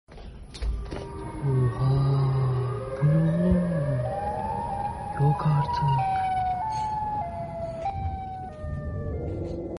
Dışardan siren sesleri duydum ve... sound effects free download